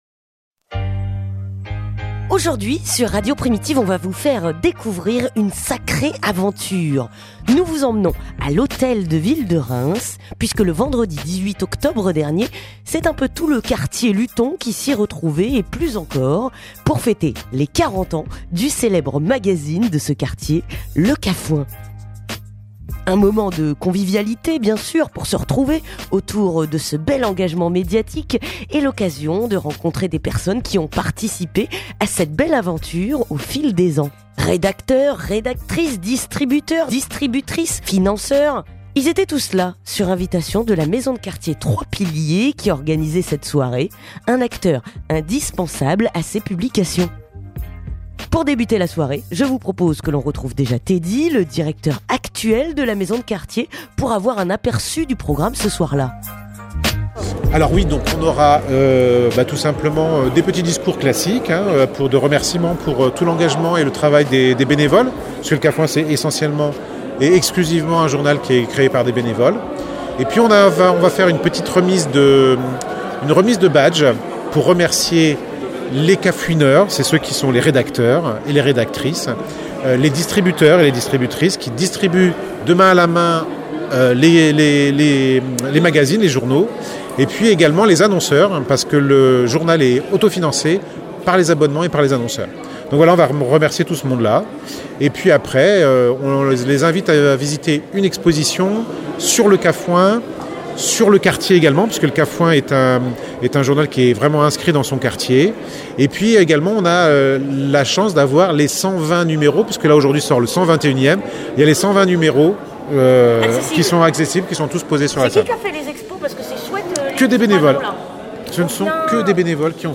Reportage à l'Hôtel de Ville de Reims.